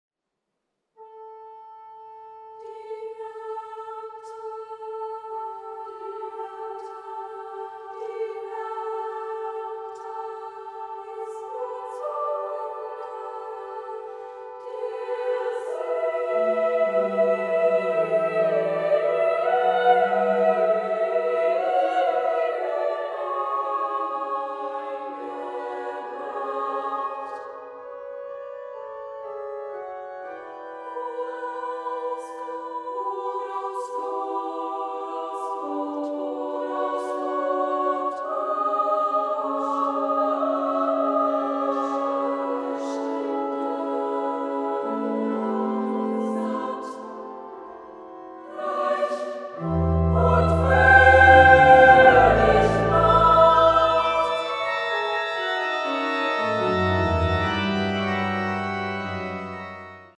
Werke für Frauen- und Kinderchor und Sololieder